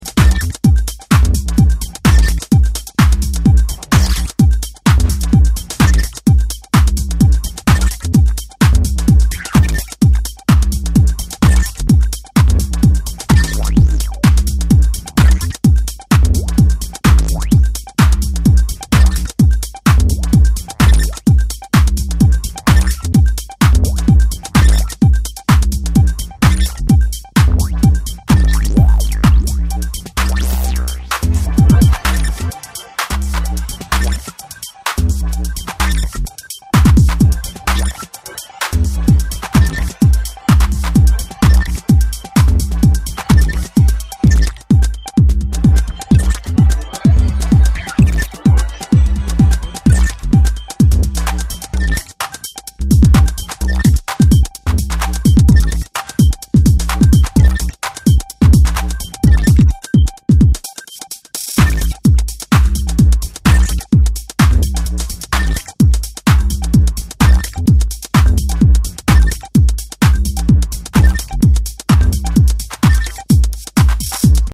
a fresh and experimental EP